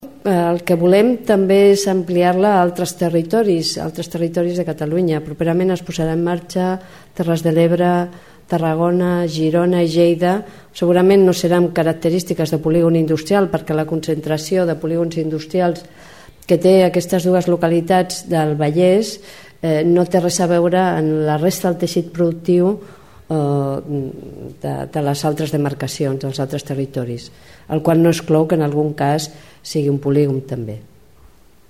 Declaracions de la consellera de Treball, Mar Serna, sobre la importància d'aprofitar tot el talent que poden aportar les dones.